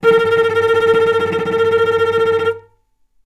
healing-soundscapes/Sound Banks/HSS_OP_Pack/Strings/cello/tremolo/vc_trm-A#4-mf.aif at 01ef1558cb71fd5ac0c09b723e26d76a8e1b755c
vc_trm-A#4-mf.aif